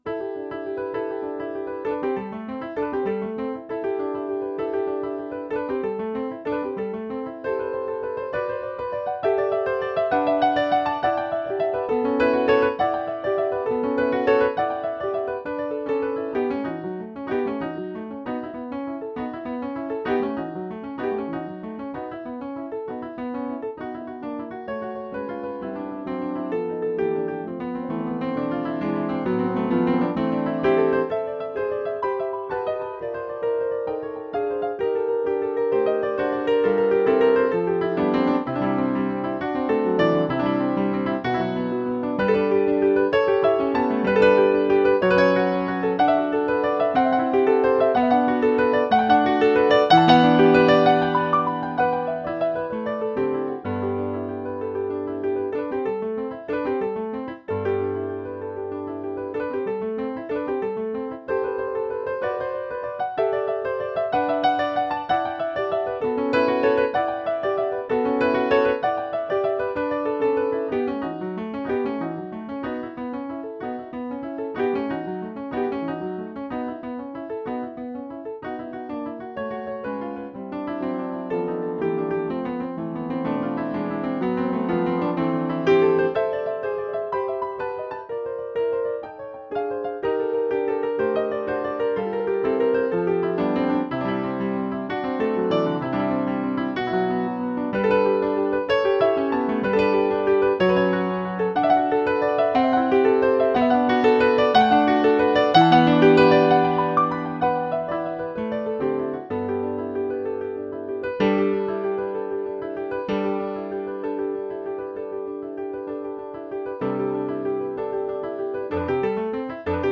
고전 모음곡의 서두에 배치되는 서주곡 형식을 따랐으며, 16분음표의 연속적인 움직임이 특징이다. 자주 등장하는 장식음표(몰덴테, 프랄트릴러)는 마디 첫머리에 연주된다.